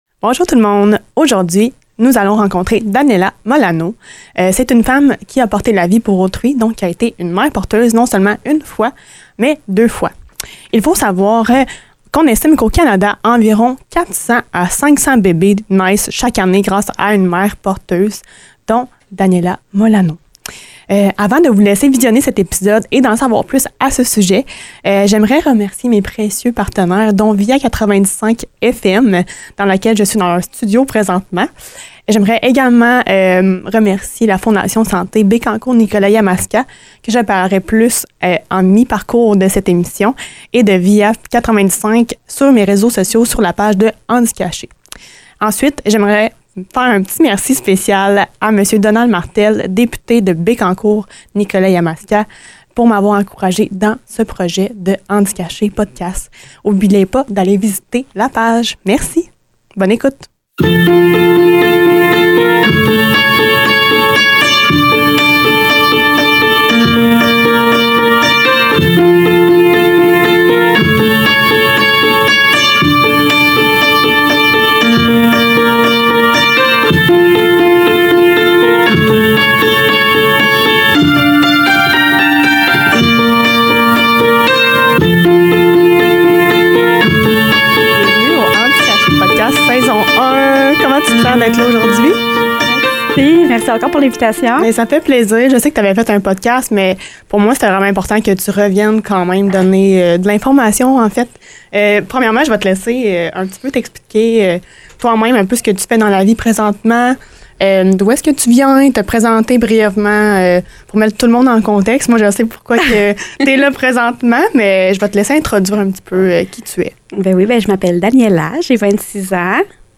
C’est une conversation honnête, remplie d’humanité, qui met de l’avant l’importance du respect, de la communication et de l’écoute dans tout ce processus.